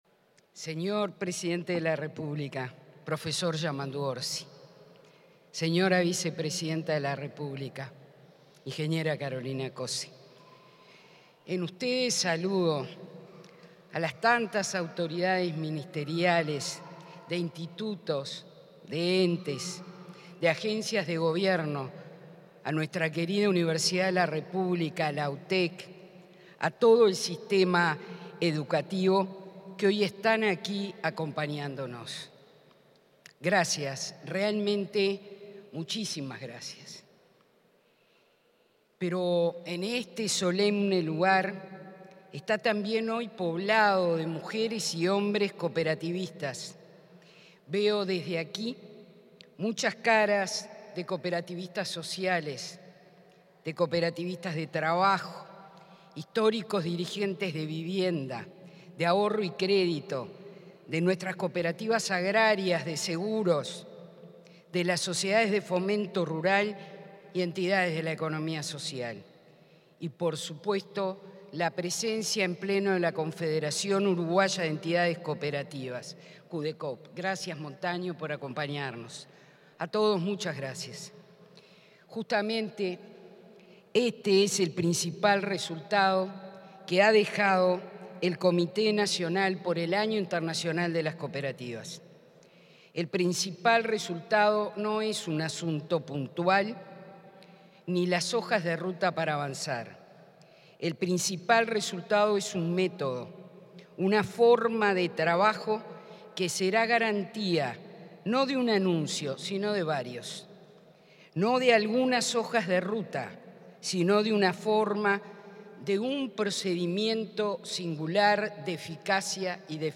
Palabras del ministro de Trabajo y Seguridad Social, Juan Castillo y la presidenta del Inacoop, Graciela Fernández
Palabras del ministro de Trabajo y Seguridad Social, Juan Castillo y la presidenta del Inacoop, Graciela Fernández 07/10/2025 Compartir Facebook X Copiar enlace WhatsApp LinkedIn El Instituto Nacional del Cooperativismo presentó el cierre de actividades del Comité Nacional por el Año Internacional de las Cooperativas y las futuras líneas de acción. En ese contexto, se expresaron el ministro Juan Castillo y la presidenta de Inacoop, Graciela Fernández.